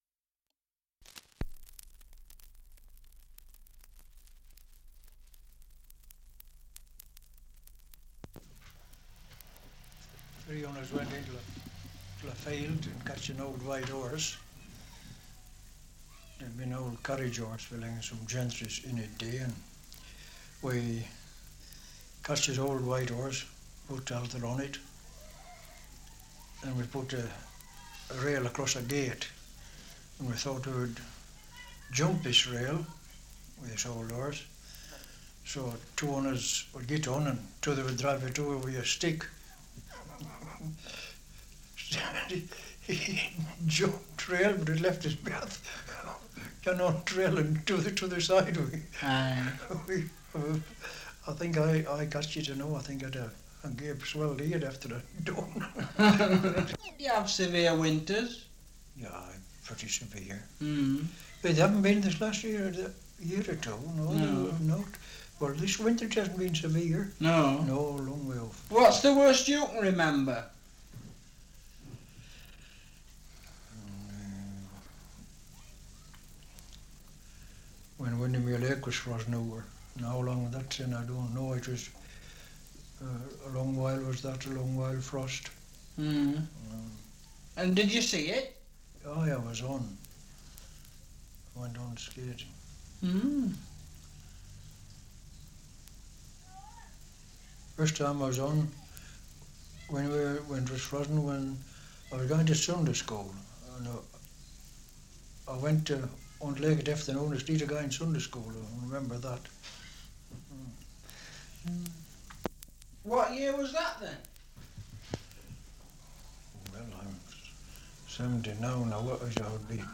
Survey of English Dialects recording in Cartmel, Lancashire
78 r.p.m., cellulose nitrate on aluminium